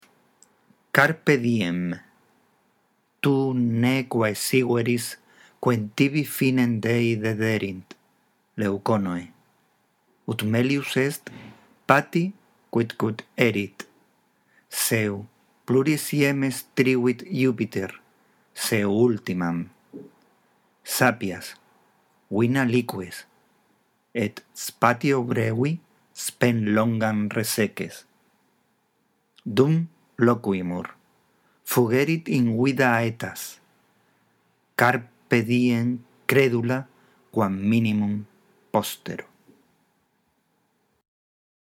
Para que tu lectura del latín mejore te sugerimos que escuches esta grabación antes de hacer tu propia lectura.